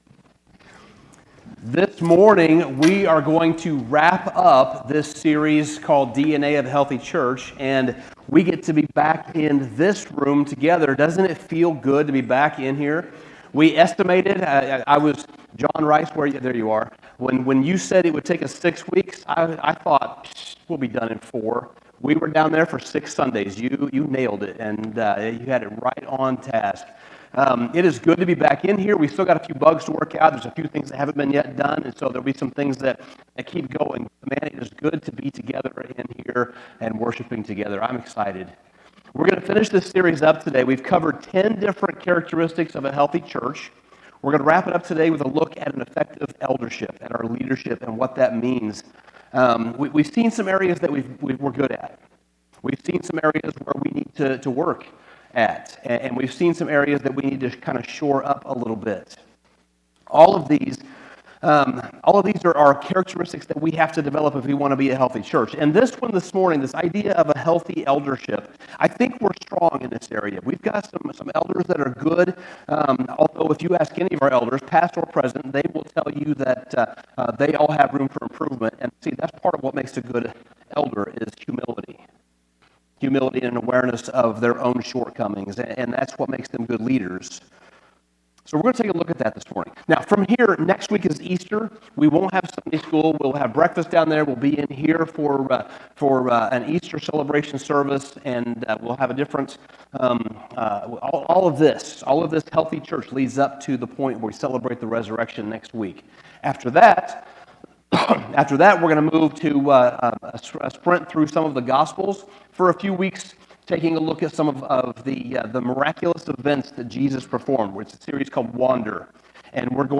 Sermon Summary The final aspect of a healthy church that we examine in this series is an effective eldership. A church will only be as healthy as its leaders are, so our leaders must be spiritually healthy and mature disciples.